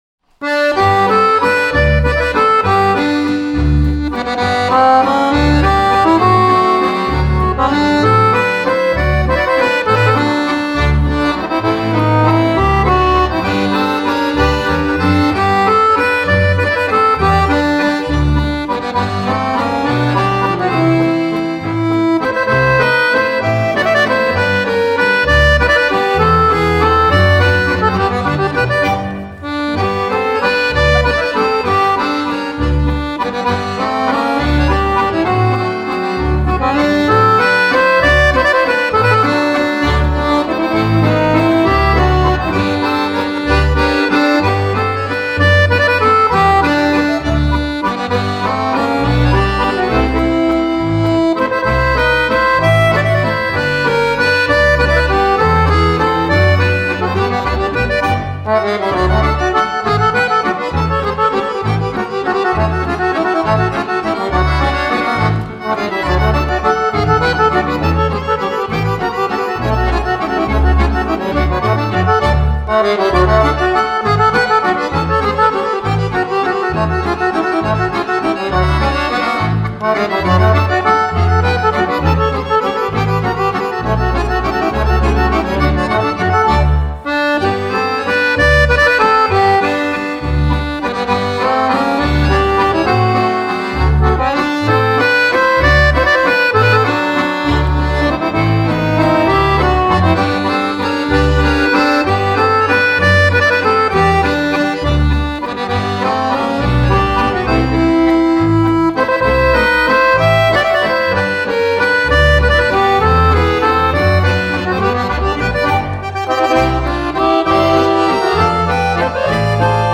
Kapelle Laimbacher Vater und Söhne: Im Spätherbst (Ländler-Walzer)